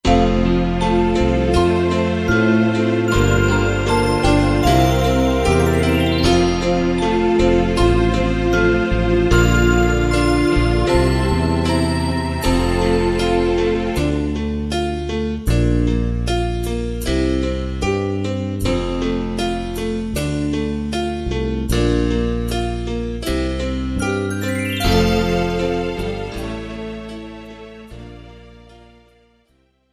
This is an instrumental backing track cover.
• Key – C
• Without Backing Vocals
• No Fade